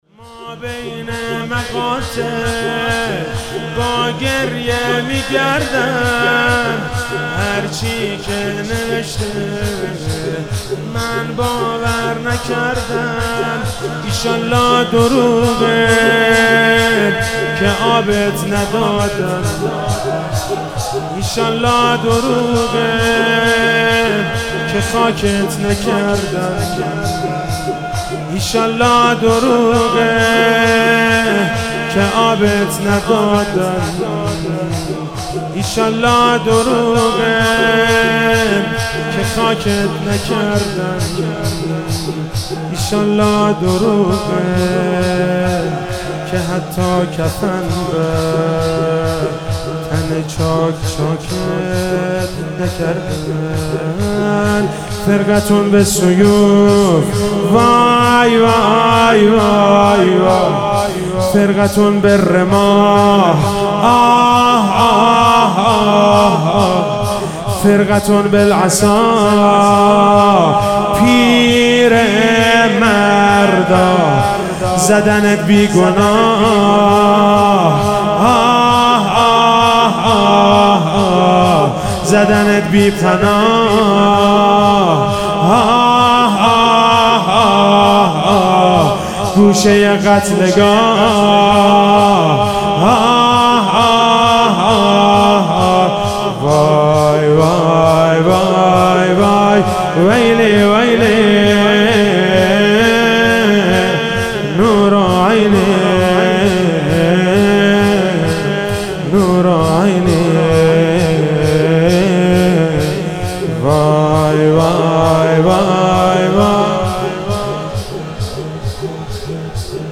محرم99 - شب عاشورا - شور - مابین مقاتل